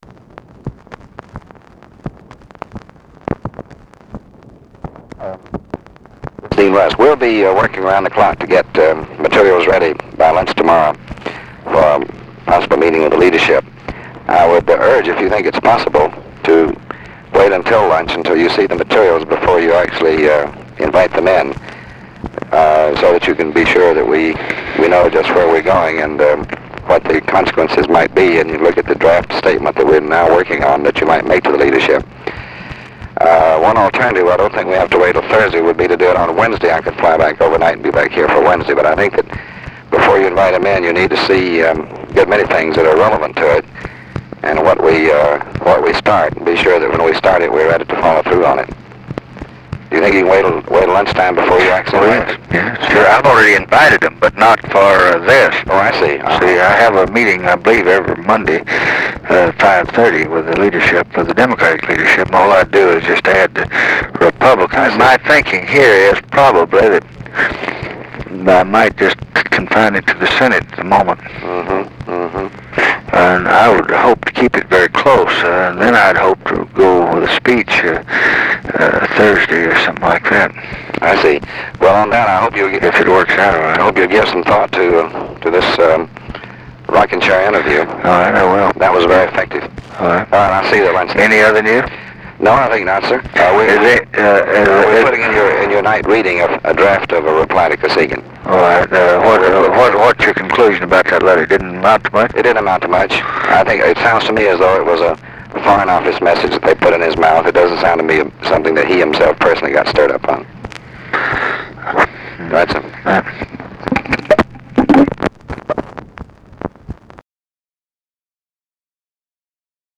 Conversation with DEAN RUSK, October 22, 1967
Secret White House Tapes